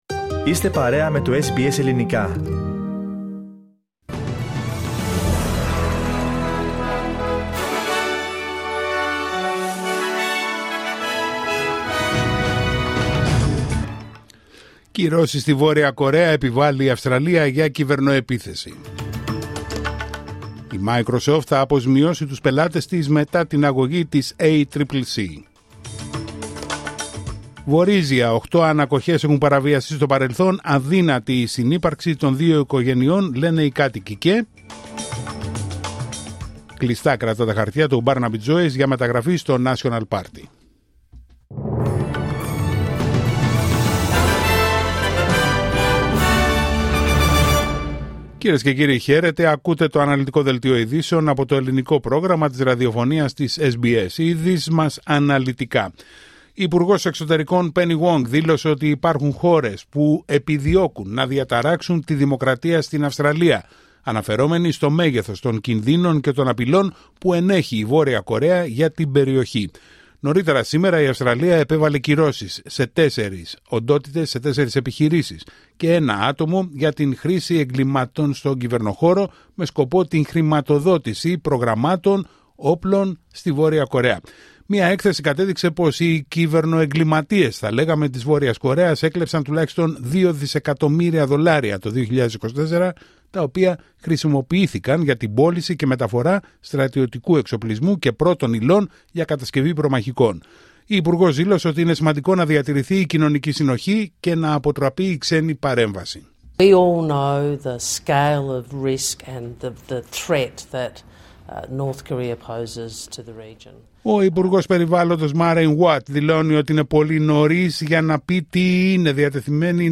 Δελτίο ειδήσεων Πέμπτη 6 Νοεμβρίου 2025